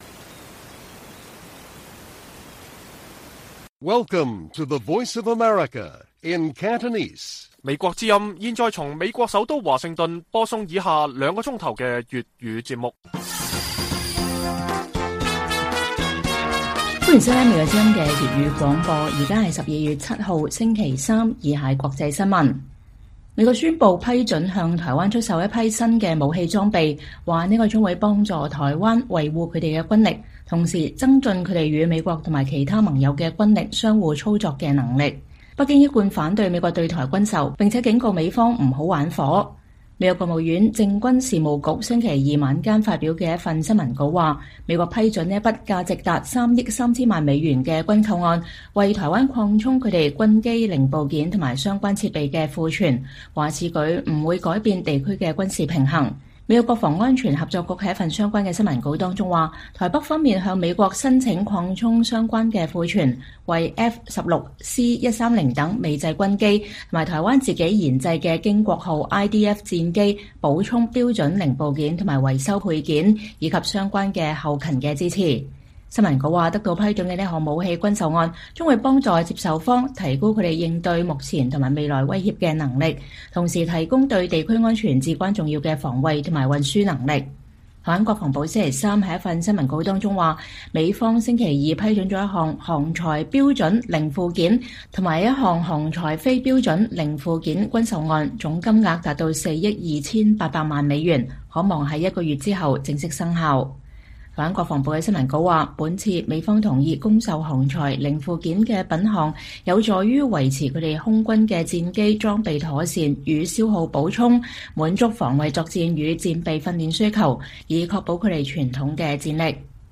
粵語新聞 晚上9-10點: 美國宣布新軍售案填補台灣軍機零部件損耗